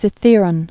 (sĭ-thîrən)